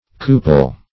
cupel - definition of cupel - synonyms, pronunciation, spelling from Free Dictionary
Cupel \Cu"pel\ (k[=u]"p[e^]l), n. [LL. cupella cup (cf. L.